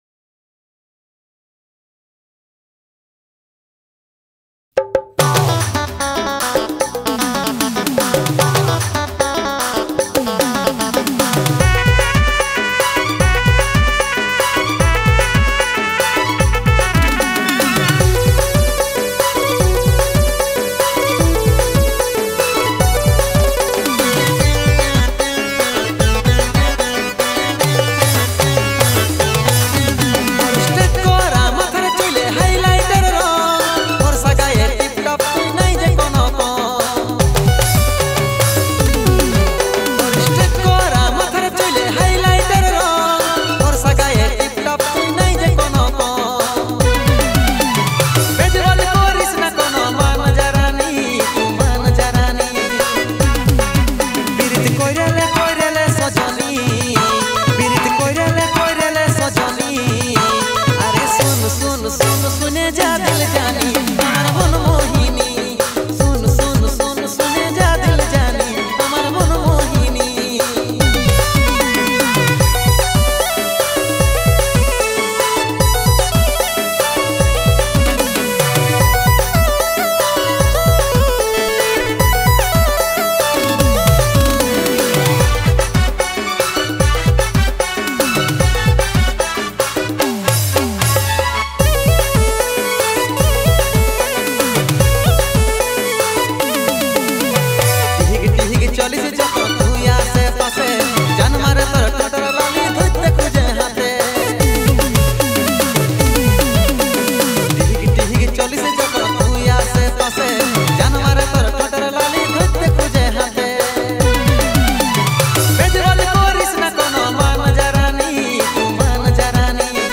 Releted Files Of Purulia Gana